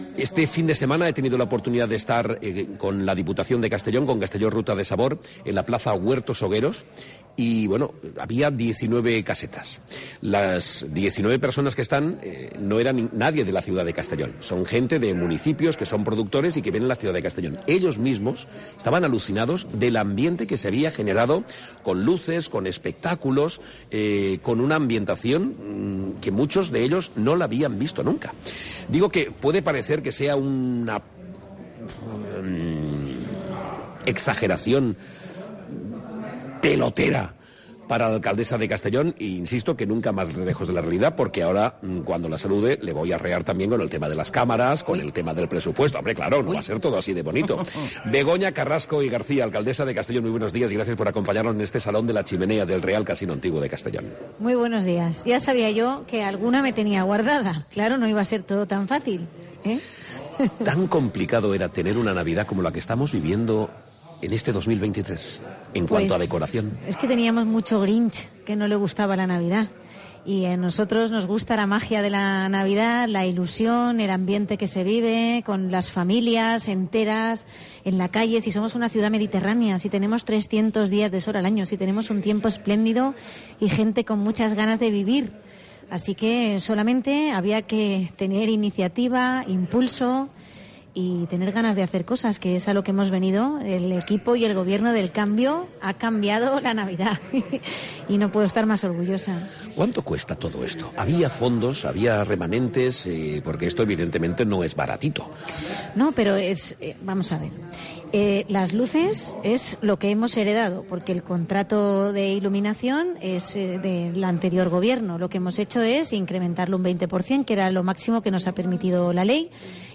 Hoy la alcaldesa, Begoña Carrasco, ha sido protagonista en COPE Castellón.